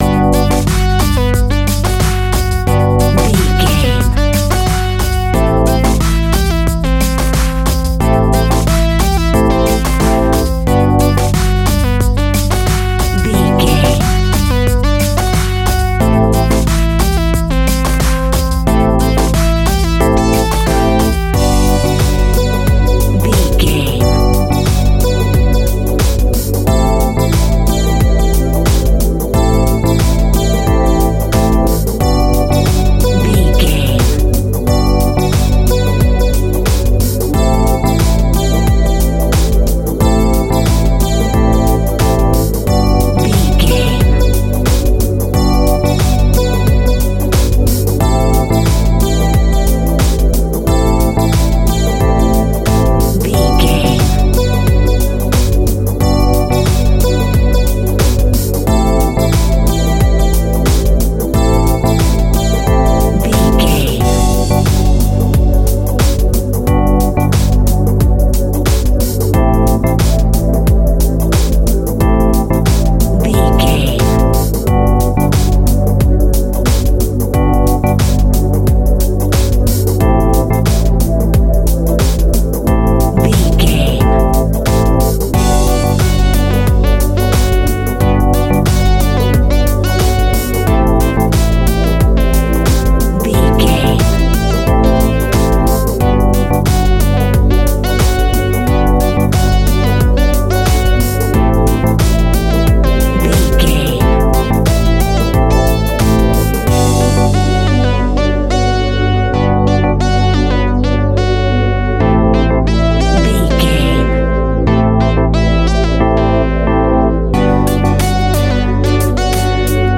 Aeolian/Minor
groovy
dreamy
peaceful
smooth
drum machine
electronic
synths
chillwave
synthwave
royalty free electronic music
synth leads
synth bass